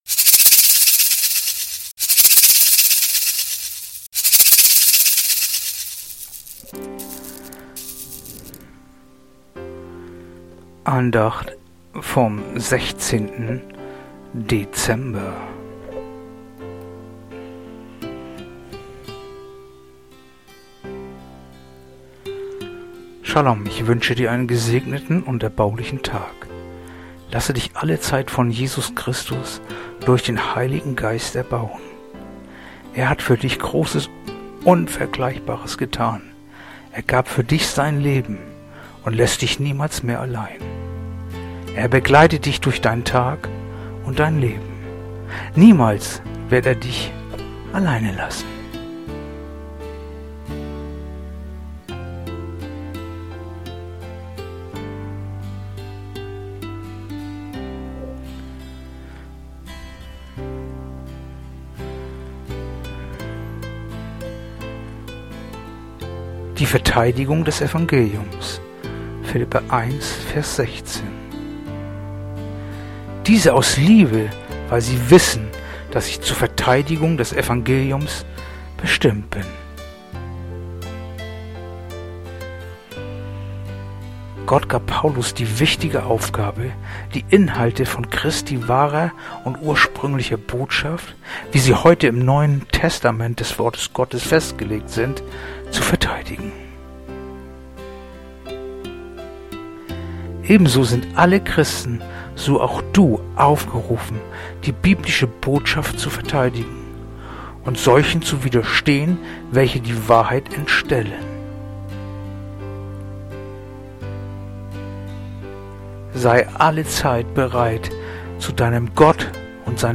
Andacht-vom16-Dezember-Philipper-1-16.mp3